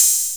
pcp_openhihat02.wav